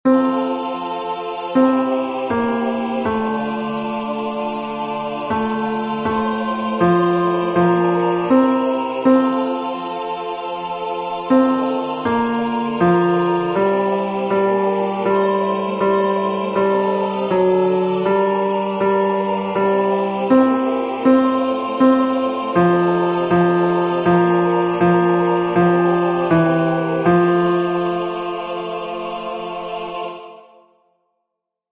with Accompaniment
MP3 Practice Files: Soprano:   Alto:   Tenor:
Number of voices: 4vv   Voicing: SATB
Genre: SacredHymn
Instruments: Piano